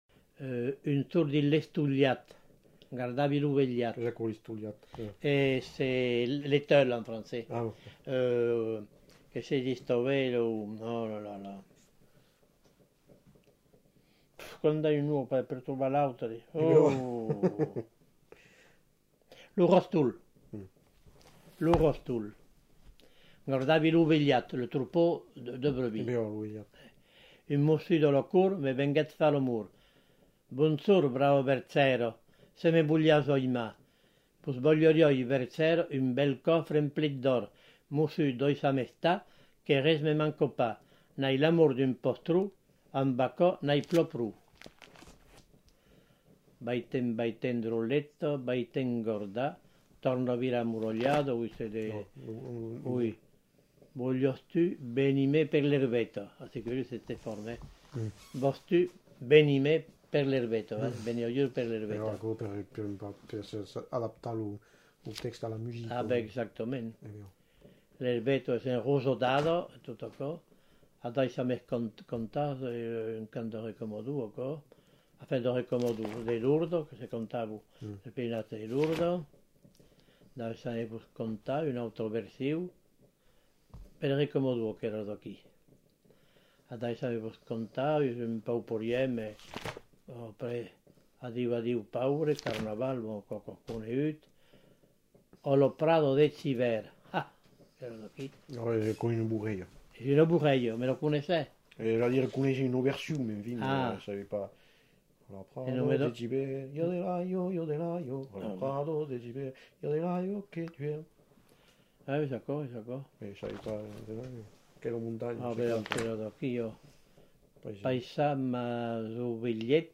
Genre : chant
Effectif : 1
Type de voix : voix d'homme
Production du son : parlé
Ecouter-voir : archives sonores en ligne